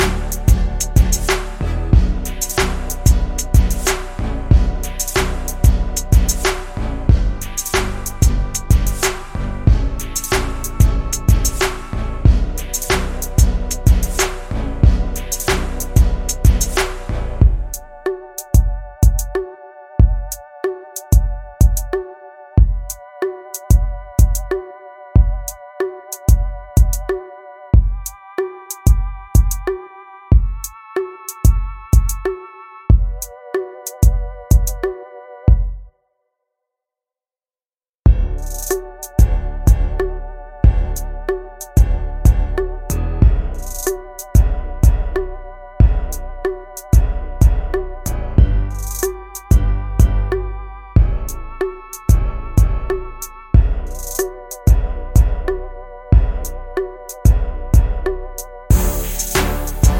no Backing Vocals R'n'B / Hip Hop 3:14 Buy £1.50